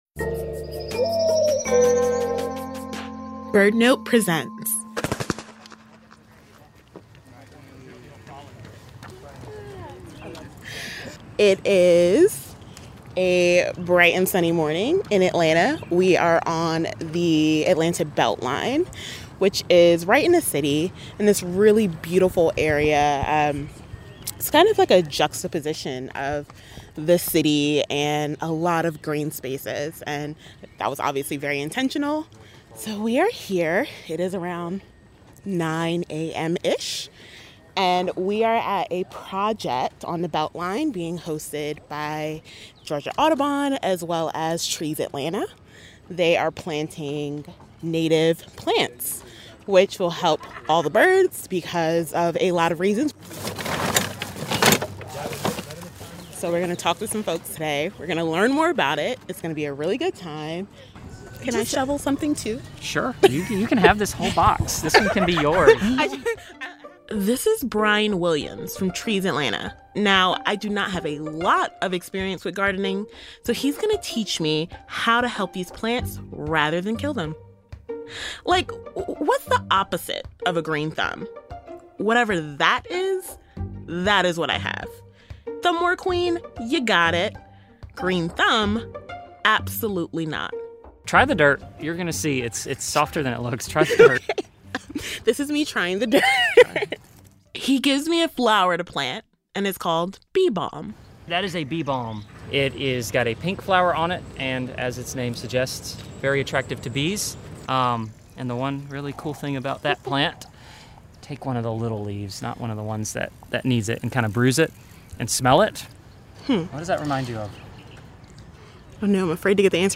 joins volunteers from Georgia Audubon and Trees Atlanta who are planting wildflowers and grasses along an urban rail trail, where she learns how native plants can provide food and shelter for birds within cities.